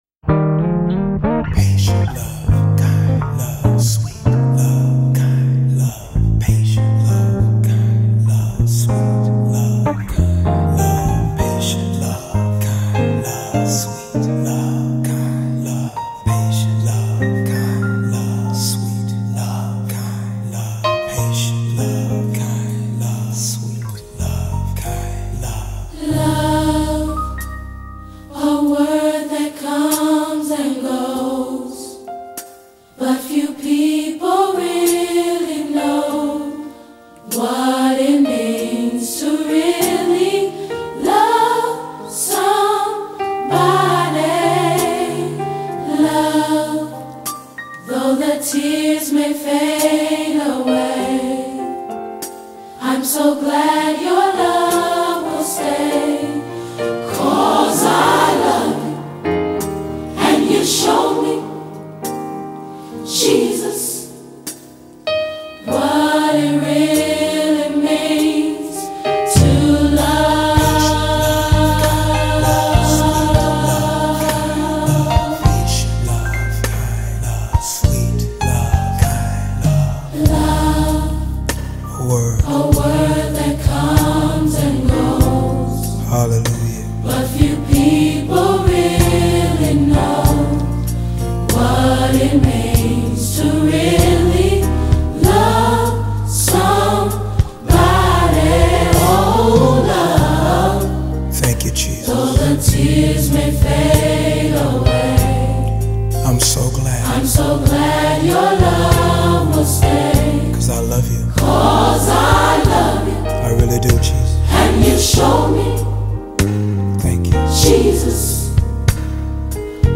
a soulful melody that lifts your spirit
Genre:Gospel